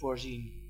Ääntäminen
US : IPA : [prɪ.ˈdɪkt]